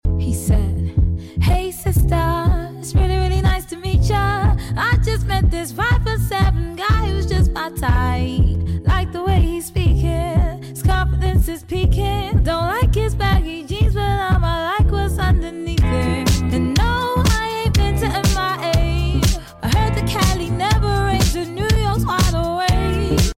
These are some of my sound effects free download